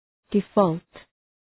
{dı’fɔ:lt}